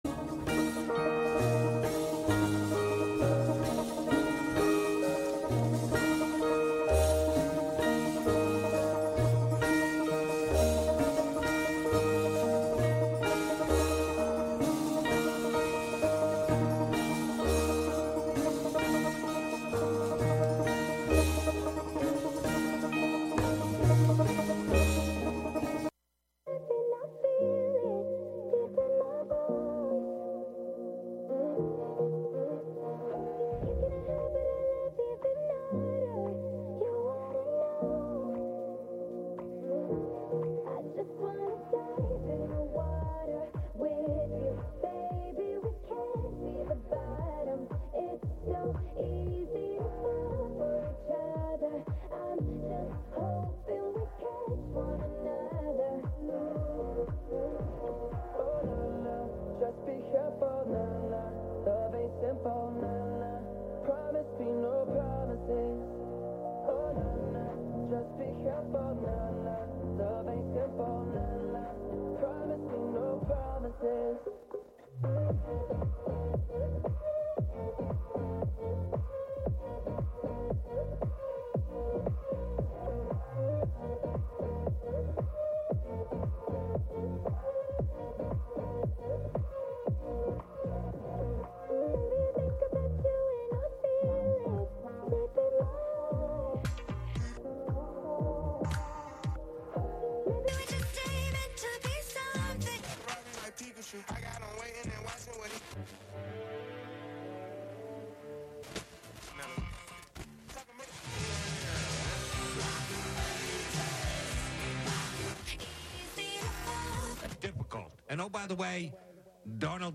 Live from Brooklyn, NY
techno